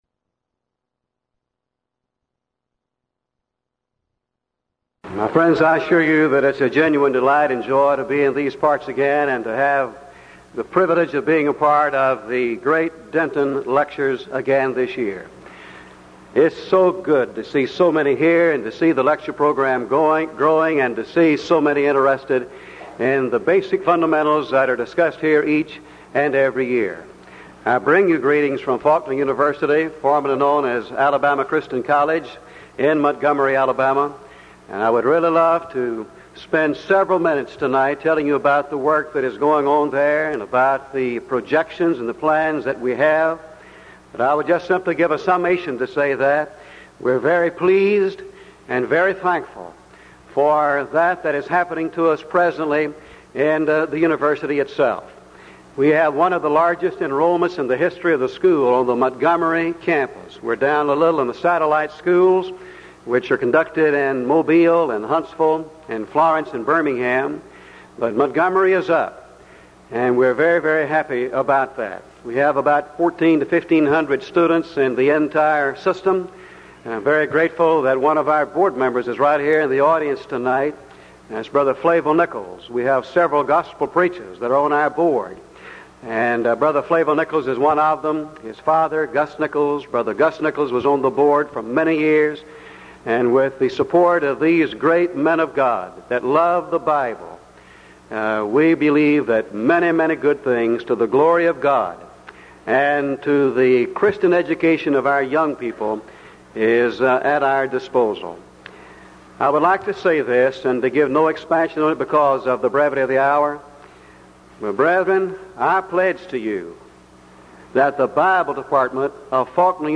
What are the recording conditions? Event: 1985 Denton Lectures